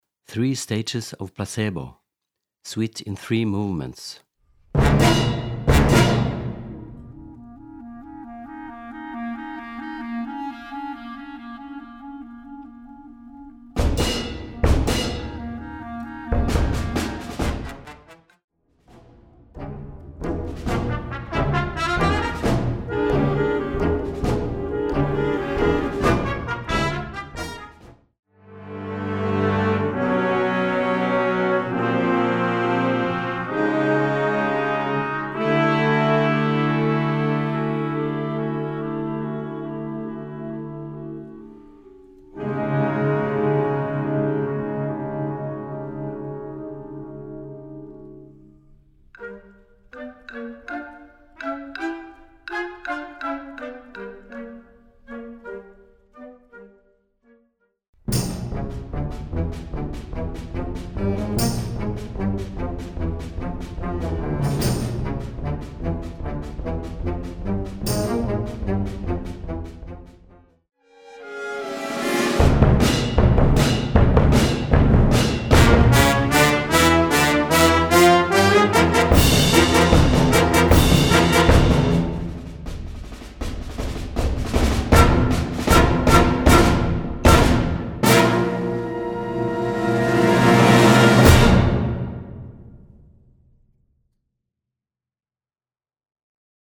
Gattung: Suite in Three Movements
Besetzung: Blasorchester